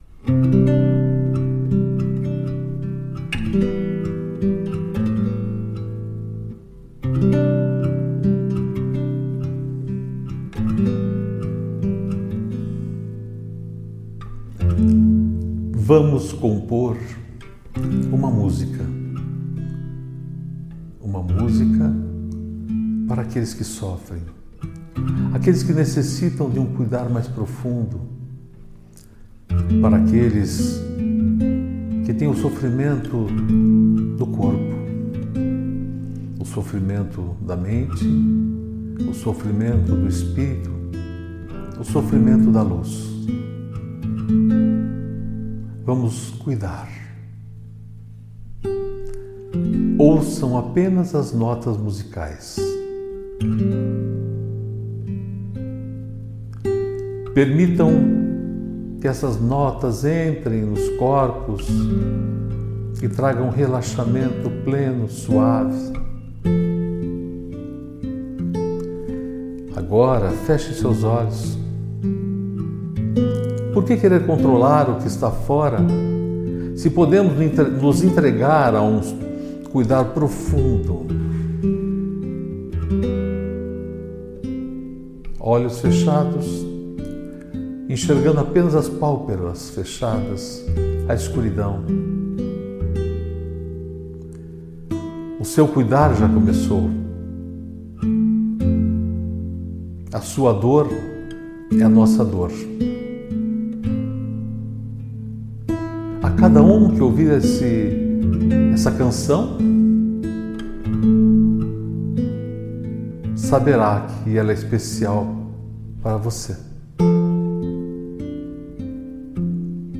Estes áudios são chamados de transmutação pois trazem uma frequência que vai além da meditação. Escute sempre que desejar, perceba na voz, a leveza e suavidade para todos os momentos.